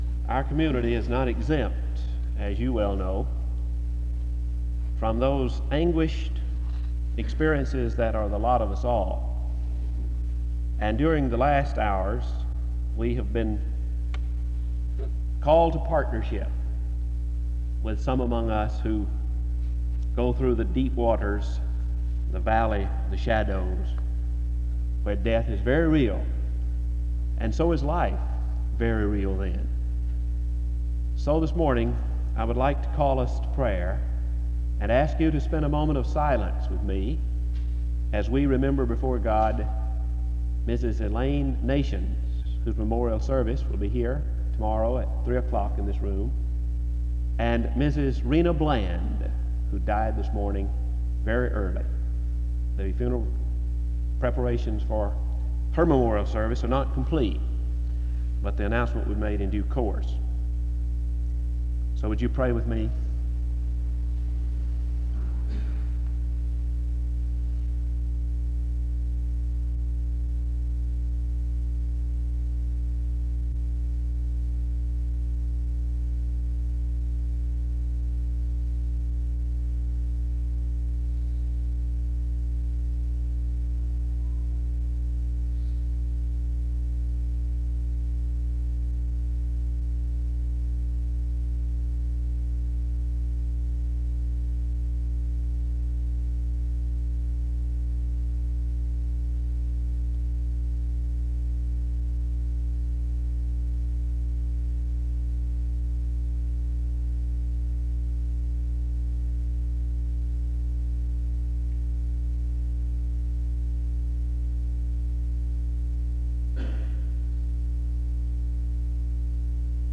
Music plays from 4:35-7:10.